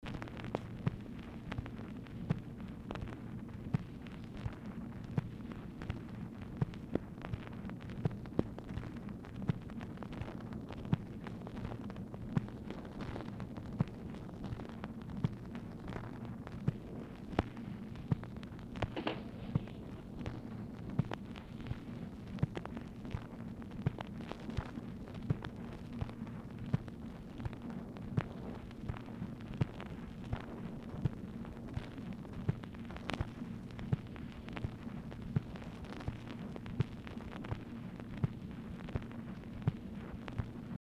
Telephone conversation # 4981, sound recording, OFFICE NOISE, 8/17/1964, time unknown | Discover LBJ
Format Dictation belt
Specific Item Type Telephone conversation